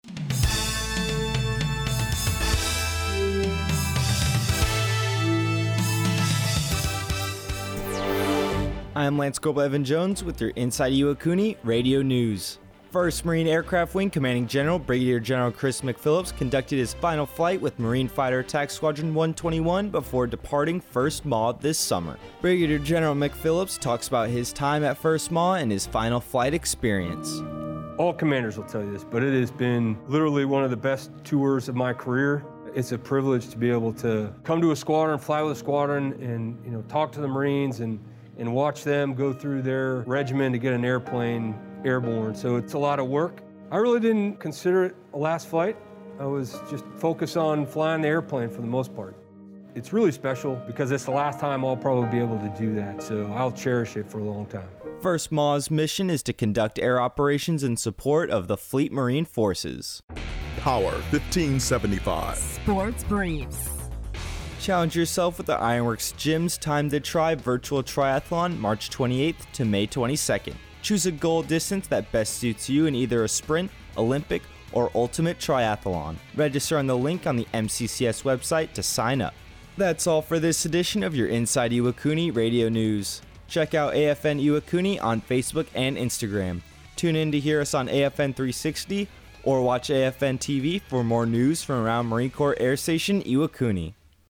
Inside Iwakuni Radio Newscast of U.S. Marine Corps Brig. Gen. Chris A. McPhillips, Commanding General, 1st Marine Aircraft Wing, flies an F-35B Lightning II Aircraft for his final flight at Marine Corps Air Station Iwakuni.